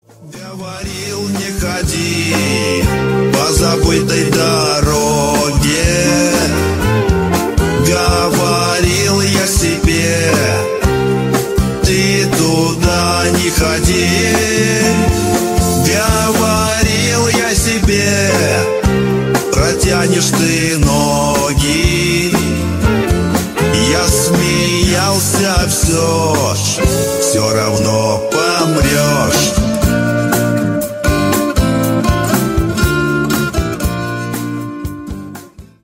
• Качество: 320, Stereo
Cover
Нейросеть